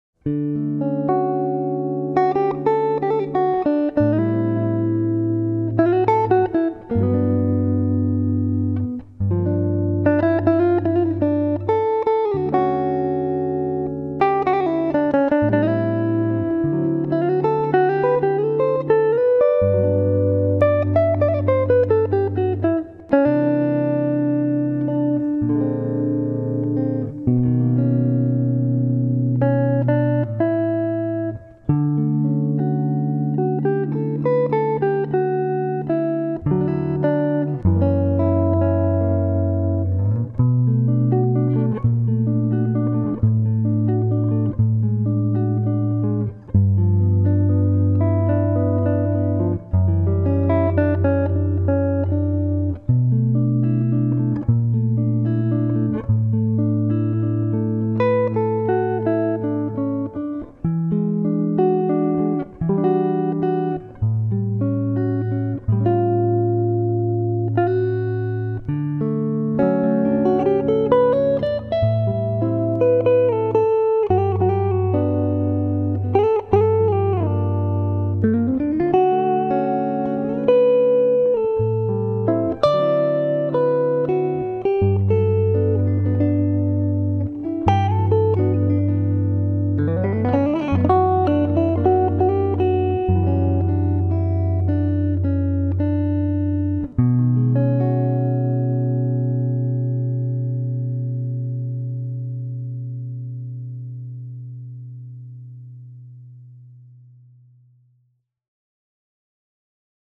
Jazz-Ton - Fingerpicker DE
Danach habe ich oft sogar meinen Akustikverstärker (SR Jam 150+) genommen, was auch sehr gut geht. Z.B. hier mit 'ner Eastman ER 3 aufgenommen (hatte ich vor Jahren schon mal gepostet):